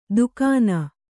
♪ dukāna